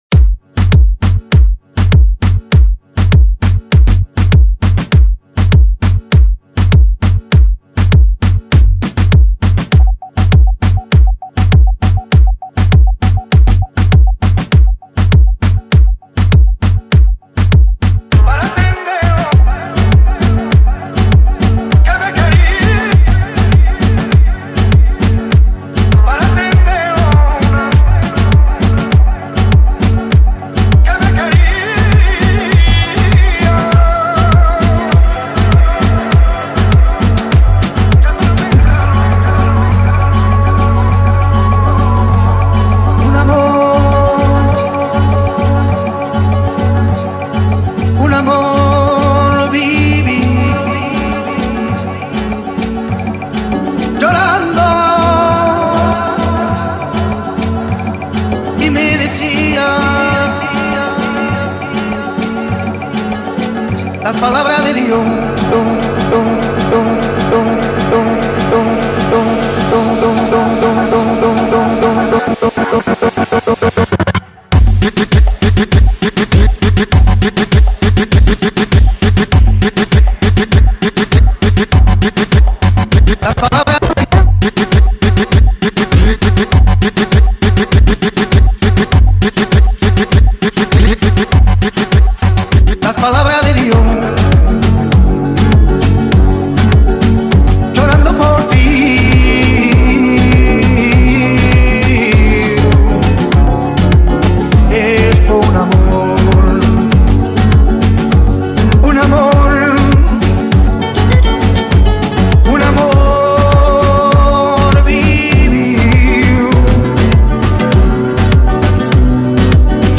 آهنگ شاد ریمیکس
برای دانلود ریمیکس های شاد ایرانی (کلیک کنید)